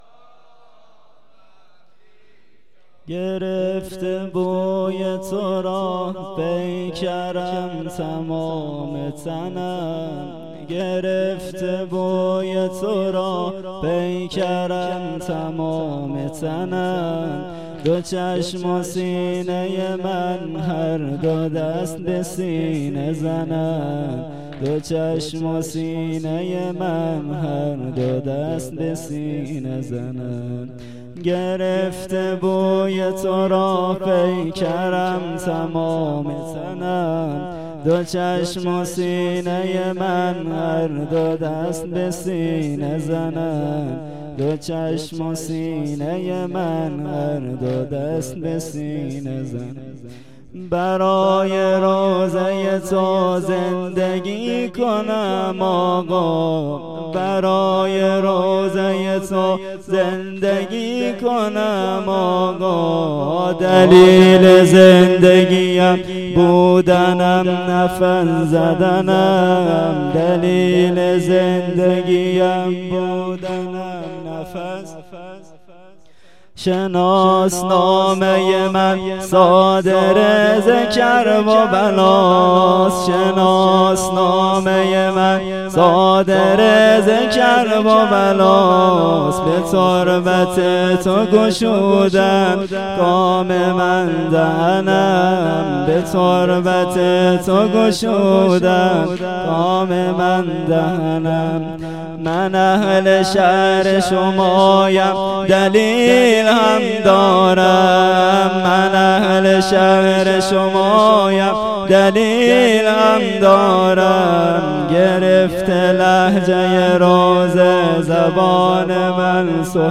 خیمه گاه - هیئت قتیل العبرات - مداحی
شب دوم محرم